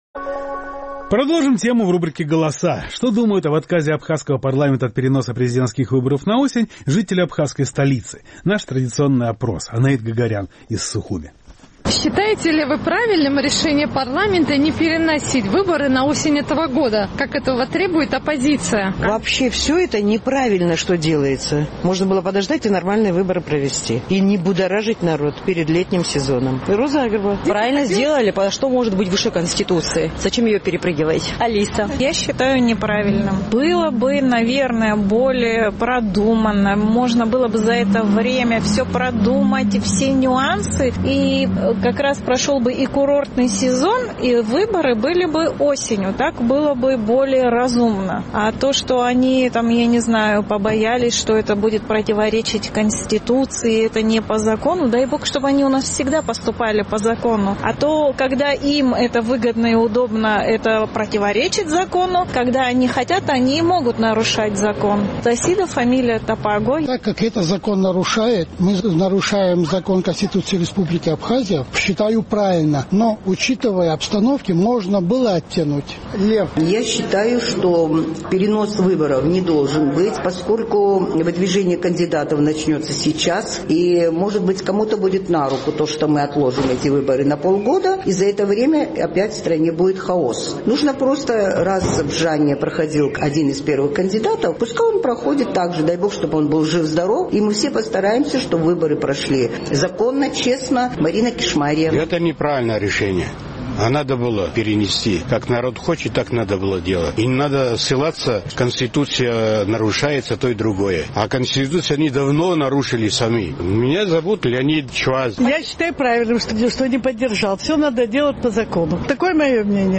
Наш сухумский корреспондент поинтересовалась у местных жителей, считают ли они правильным решение парламента не переносить выборы на осень этого года, как этого требует оппозиция.